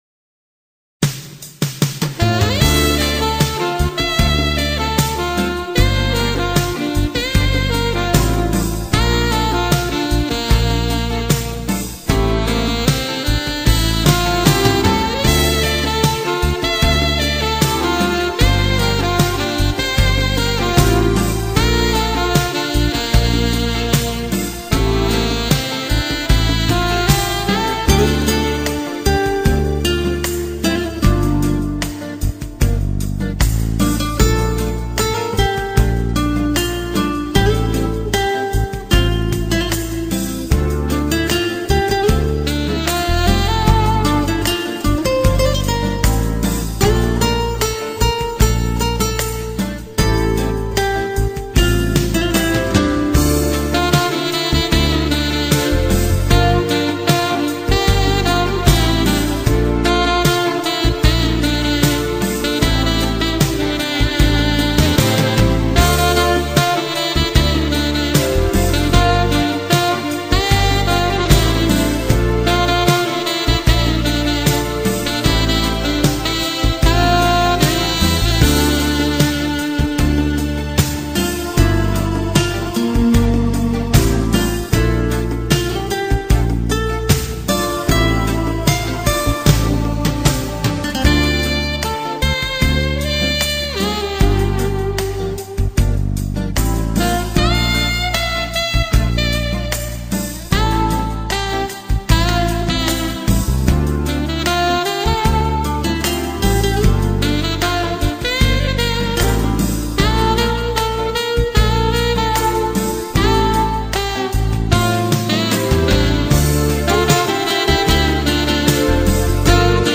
موسیقی بی کلام آهنگ بی کلام